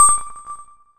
beep2.wav